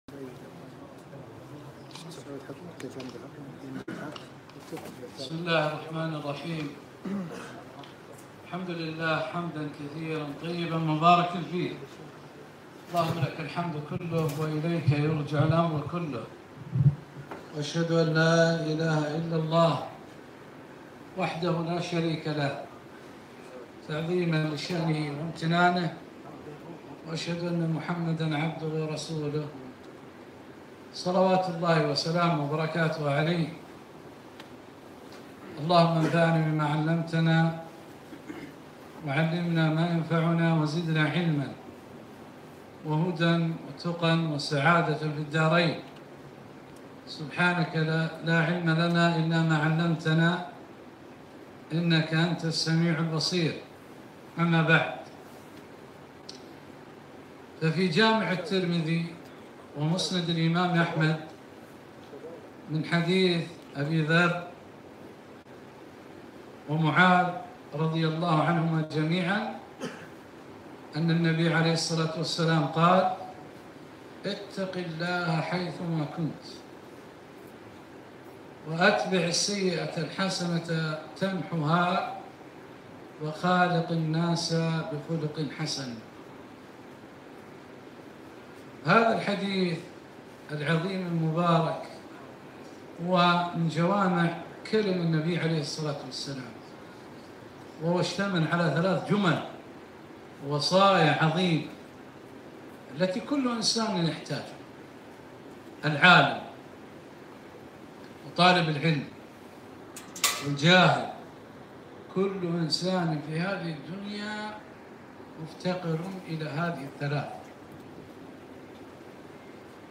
محاضرة - ( اتقِ ﷲ حيثما كنت )